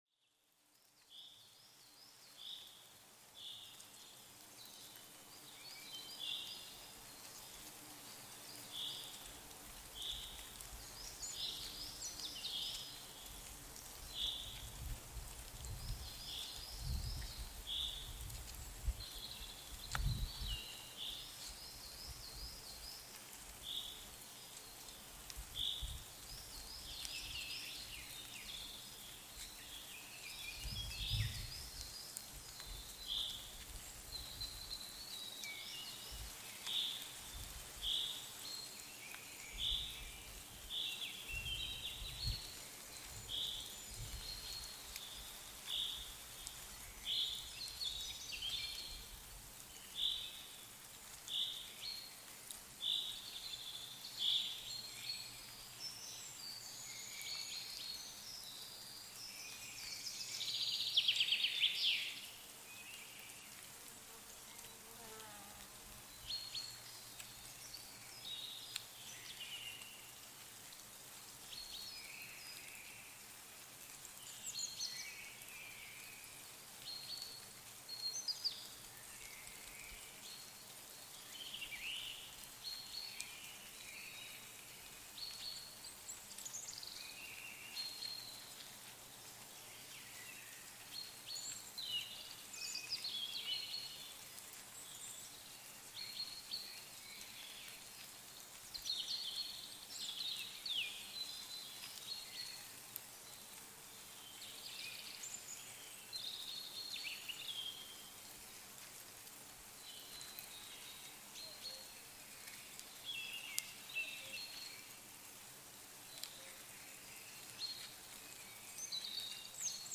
STRESS-HEILUNG: Ameisen-Frequenzen mit verborgenen Wald-Klängen